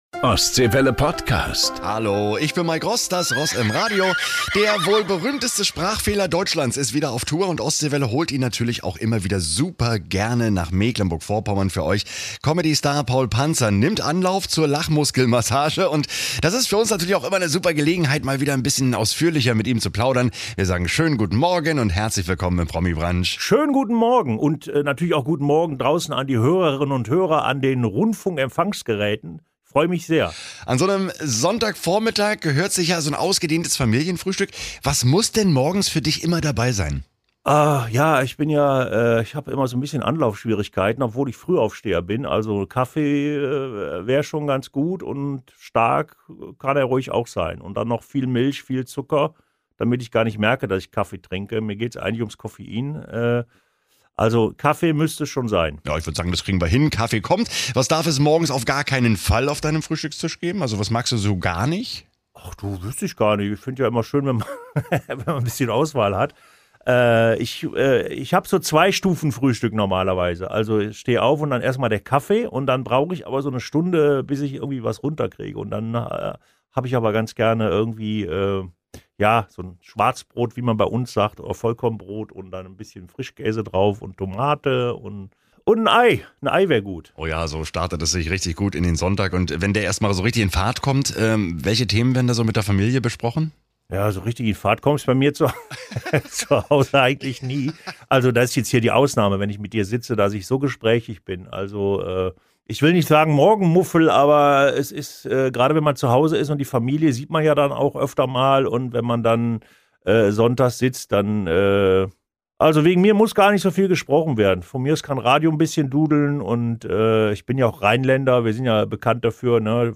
Beschreibung vor 1 Jahr Der wohl berühmteste Sprachfehler Deutschlands ist wieder auf Tour und Ostseewelle holt ihn natürlich auch immer wieder gern für euch nach Mecklenburg-Vorpommern.